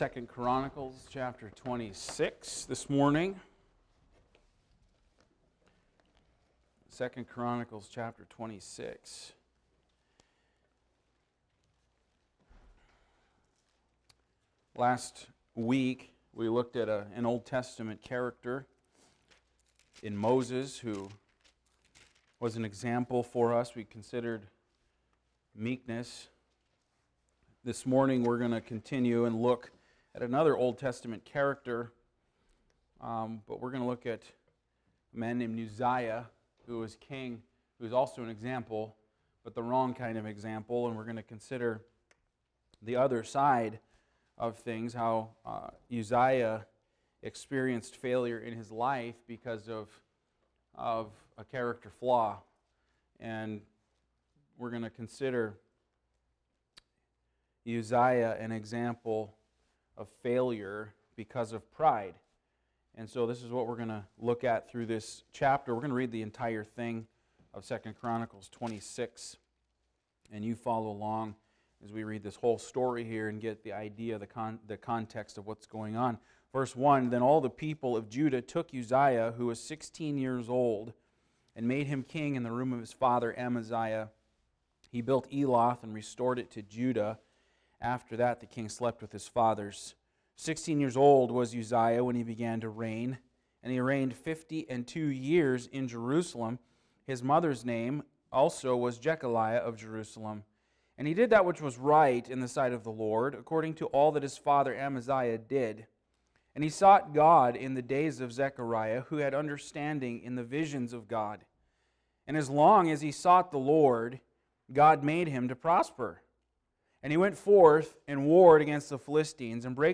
Passage: II Chronicles 26:1-23 Service Type: Sunday School